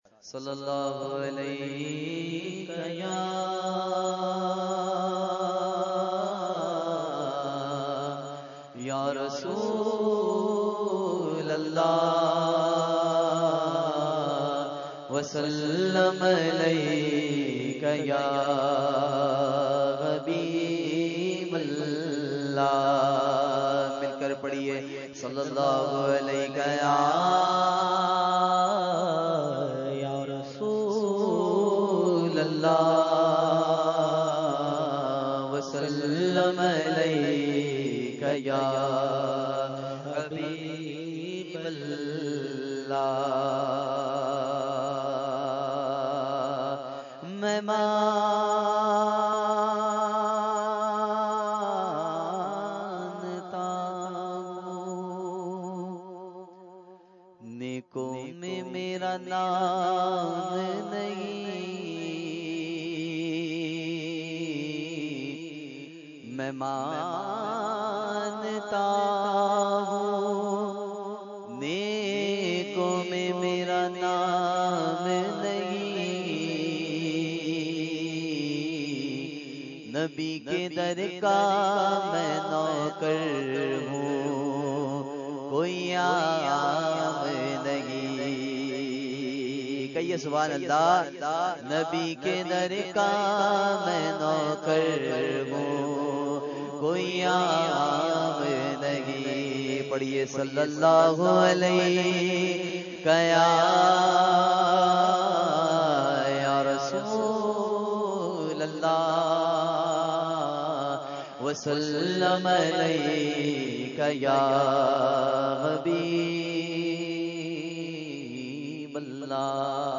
Category : Hamd | Language : UrduEvent : Mehfil PECHS Society Khi 2015